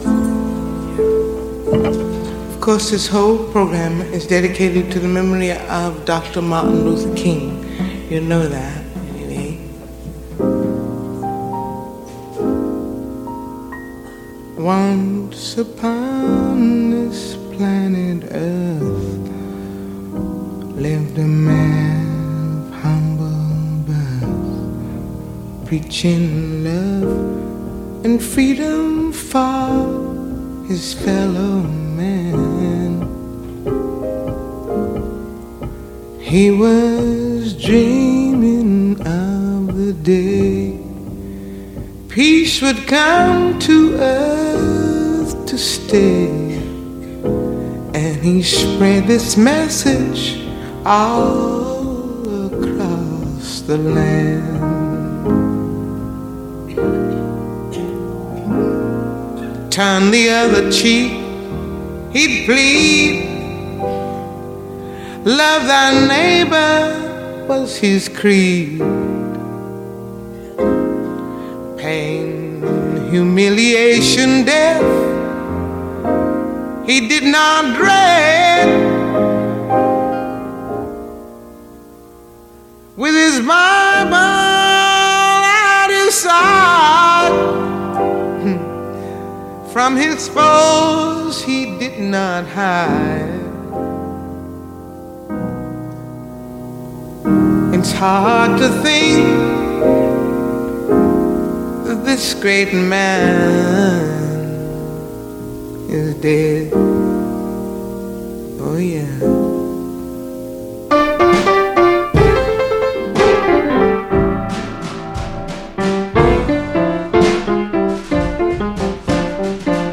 mlk-promo.wav